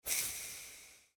Spruehschnee.mp3